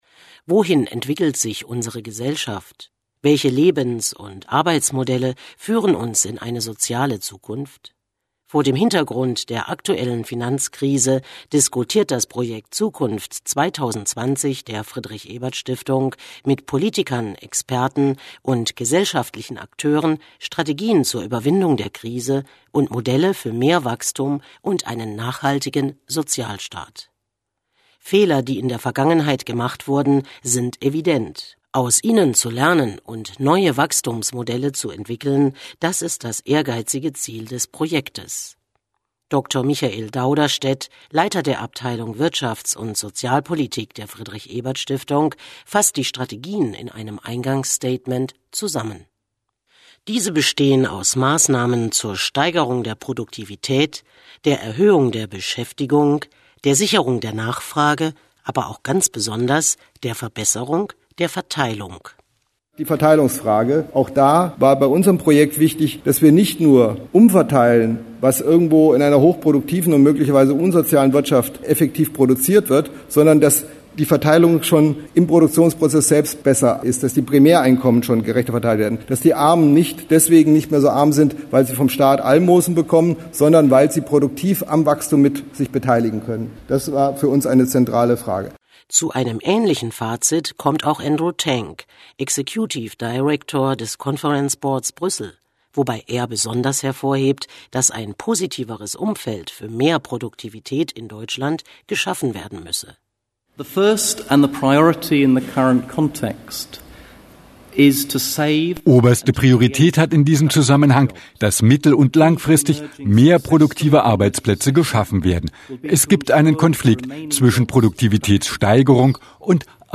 Am 10. Juni 2009 wurden in Berlin auf der öffentlichen Konferenz "Deutschland 2020 - aus der Krise in eine soziale Zukunft" (Programm) die Studien aus dem Projekt präsentiert und mit dem Bundesminister für Arbeit und Soziales Olaf Scholz, der ehemaligen Bundesministerin für Bildung und Forschung Edelgard Bulmahn und anderen diskutiert.
Audiobeitrag (mp3, ca. 6.10 min) hören oder als Youtube-Video (ca. 6 min) sehen und hören.